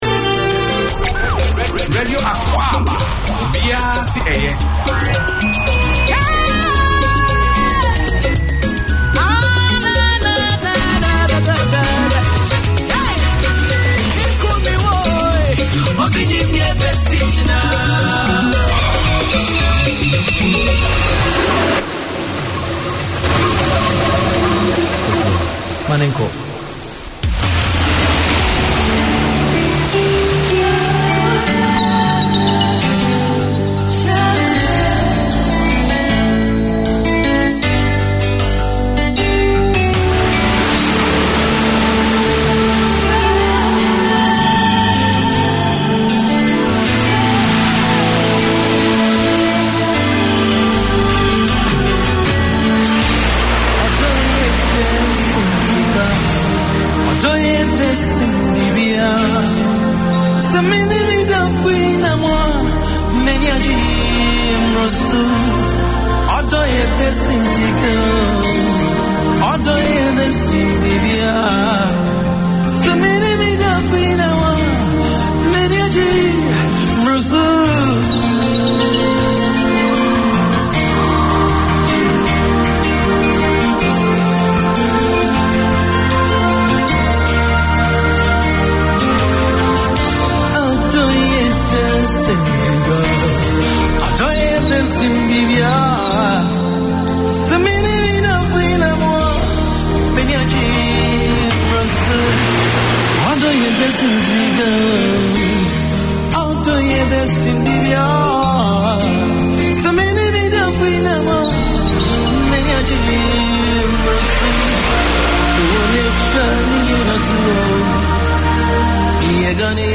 Radio Akwaaba is a programme targeted to the Ghanaian Community and all those who are interested in Ghana or African culture. It brings news, current affairs and sports reporting from Ghana along with music and entertainment.